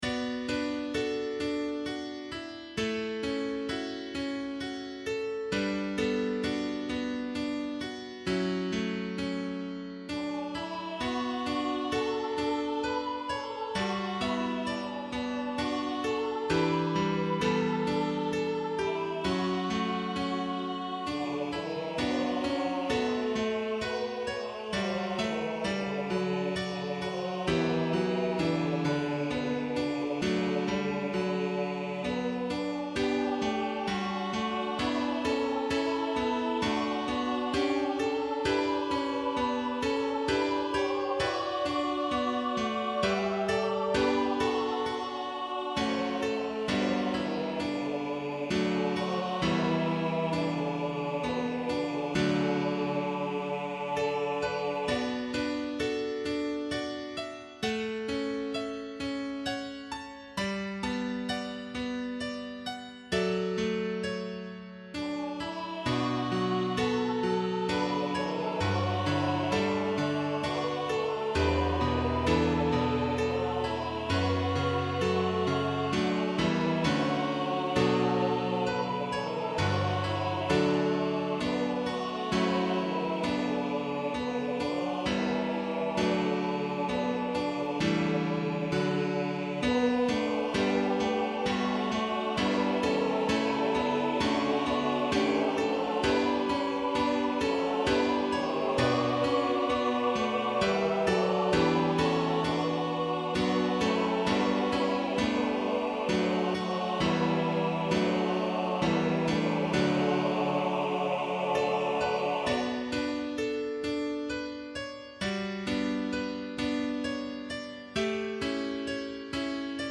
Note the second verse canon.